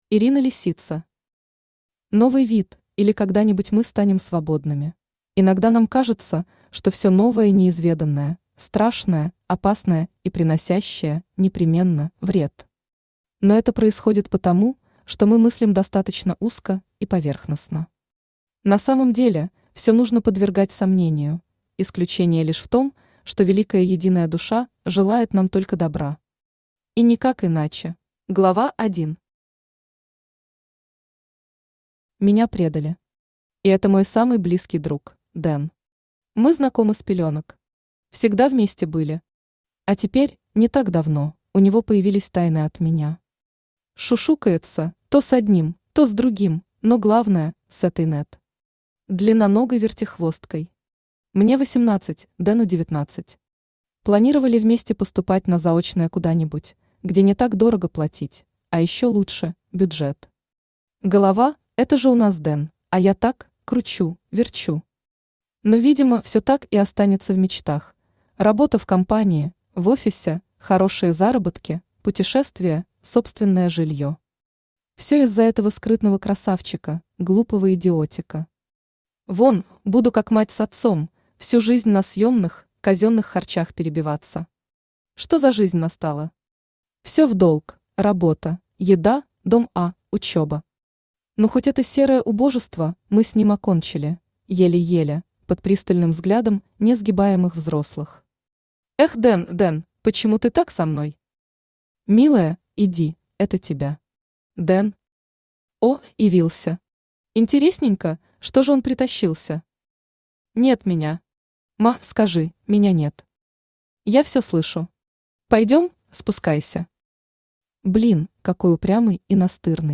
Аудиокнига Новый вид, или Когда-нибудь мы станем свободными | Библиотека аудиокниг
Aудиокнига Новый вид, или Когда-нибудь мы станем свободными Автор Ирина Лисица Читает аудиокнигу Авточтец ЛитРес.